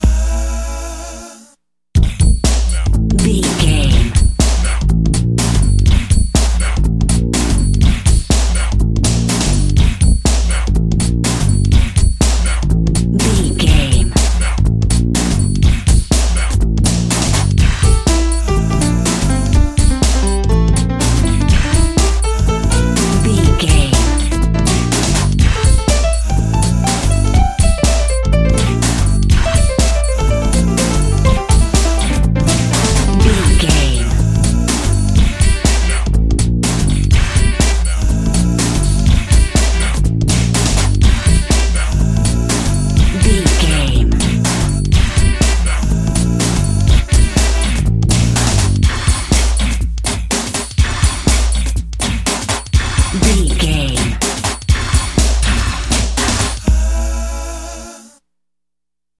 Ionian/Major
D
synthesiser
drum machine
electric guitar
drums
strings
90s